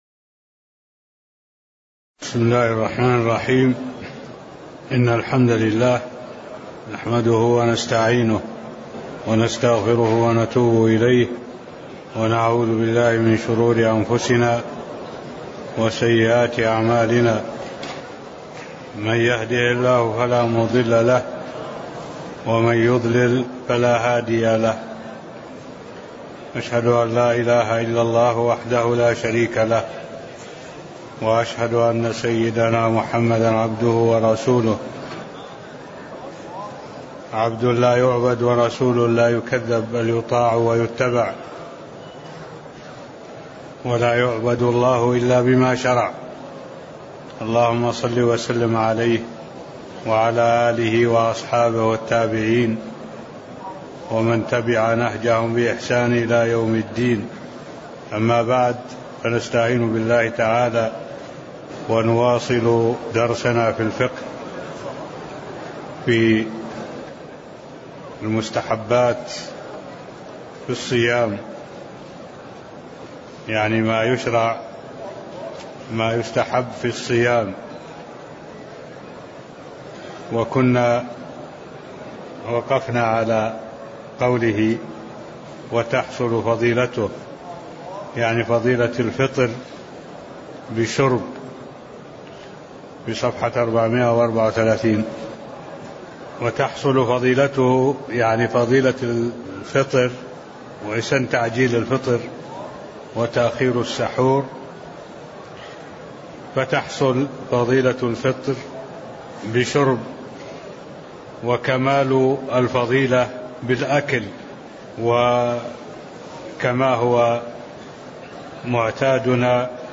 المكان: المسجد النبوي الشيخ: معالي الشيخ الدكتور صالح بن عبد الله العبود معالي الشيخ الدكتور صالح بن عبد الله العبود مستحبات الصيام (قول المصنف وتحصل فضيلته أي الفطر بشرب) (10)